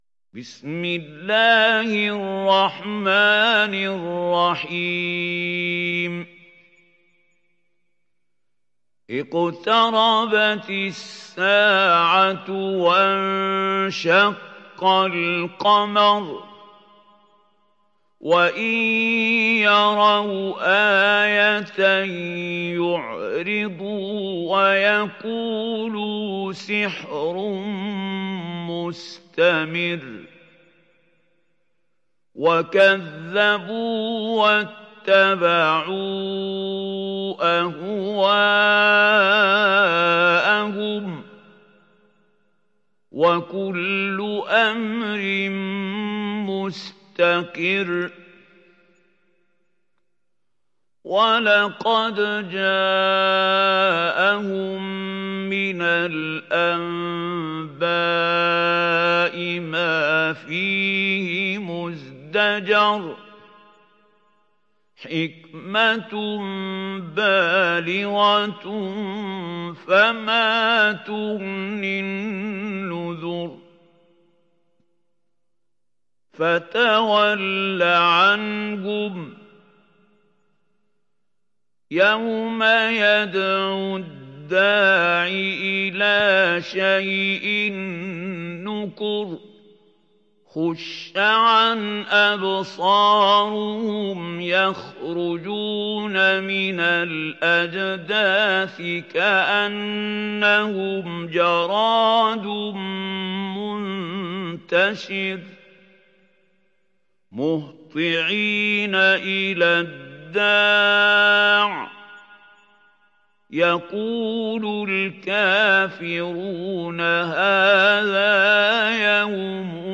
Kamer Suresi İndir mp3 Mahmoud Khalil Al Hussary Riwayat Hafs an Asim, Kurani indirin ve mp3 tam doğrudan bağlantılar dinle
İndir Kamer Suresi Mahmoud Khalil Al Hussary